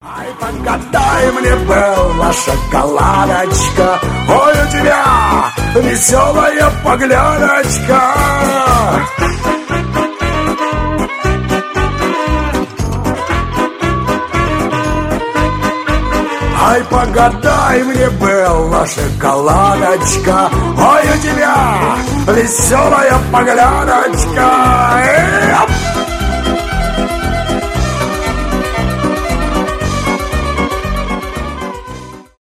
шансон , цыганская музыка